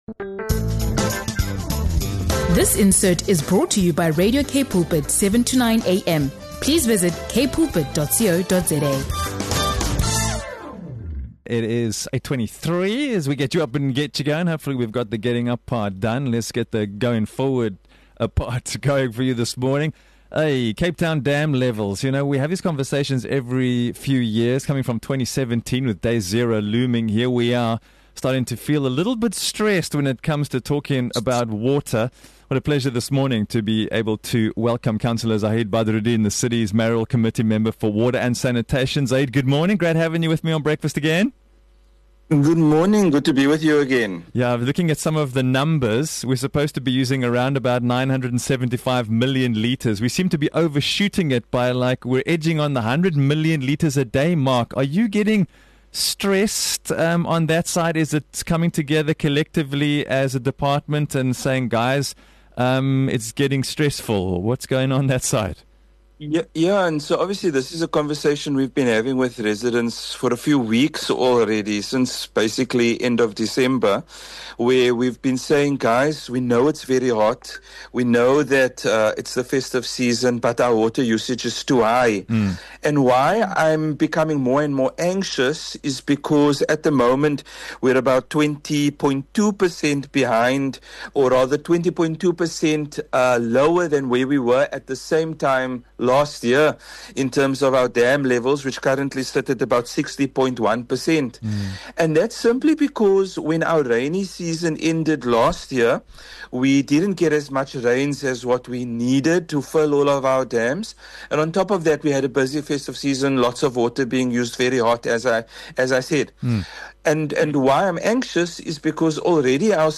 GET UP & GO BREAKFAST - INTERVIEW SPECIALS
In this crucial breakfast interview, Cape Town Mayoral Committee Member for Water and Sanitation, Councillor Zahid Badroodin, joins the show to unpack falling dam levels, rising daily water consumption, and the real risk of water restrictions returning as early as November.